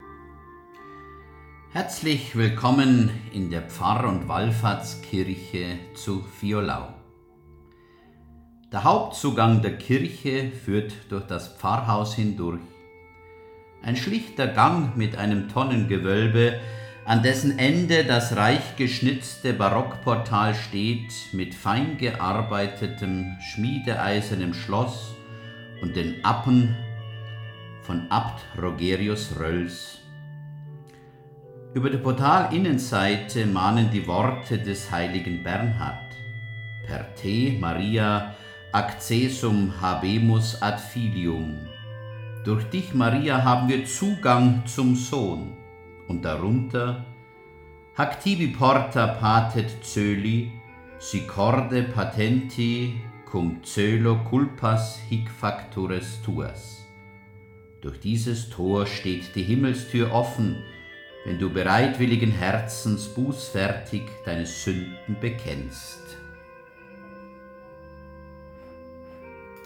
Kirchenführer Audioguide